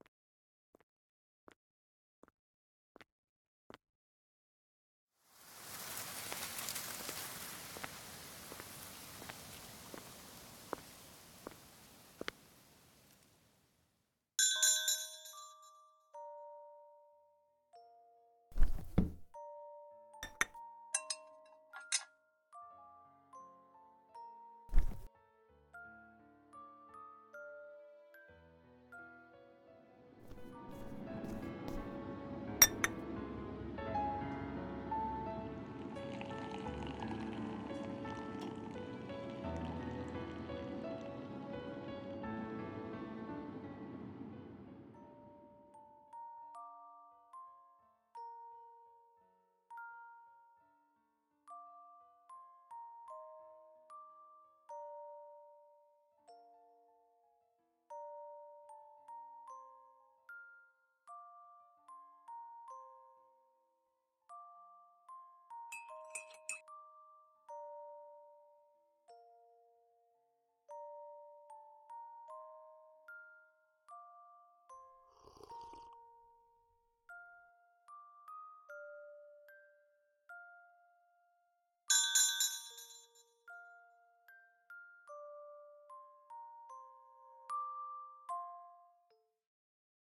【声劇】.filo(sugar)